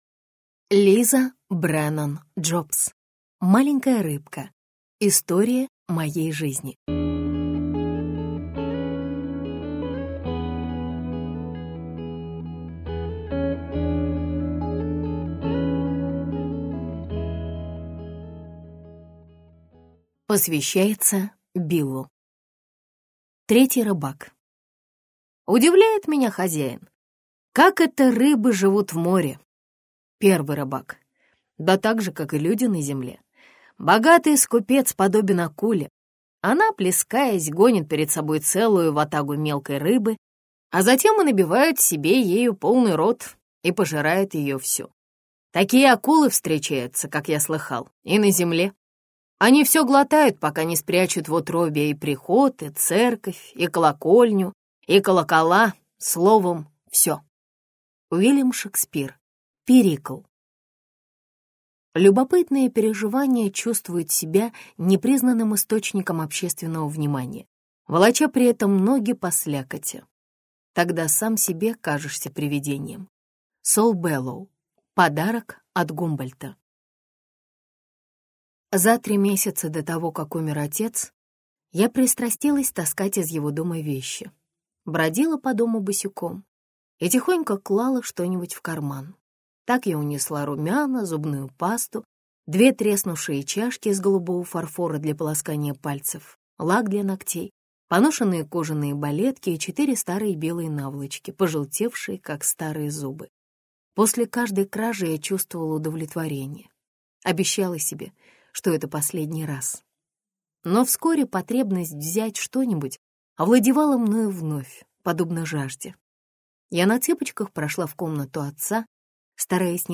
Аудиокнига Маленькая рыбка. История моей жизни | Библиотека аудиокниг